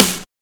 SNARE129.wav